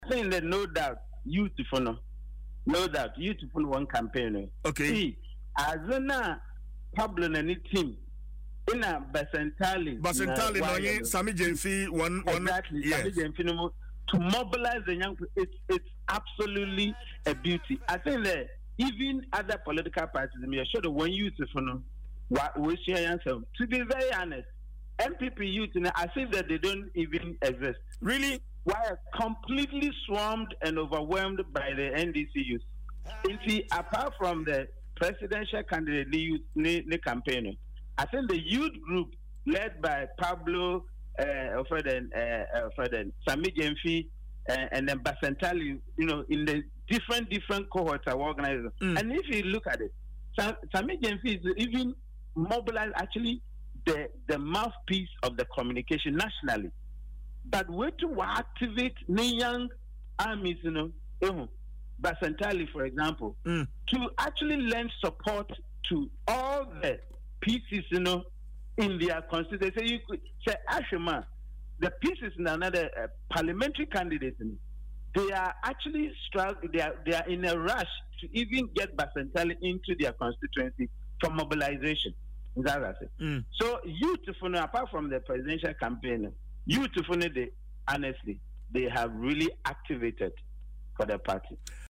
Speaking on Adom FM’s morning show, Dwaso Nsem, he asserted that, the same cannot be said about the New Patriotic Party(NPP) youth wing.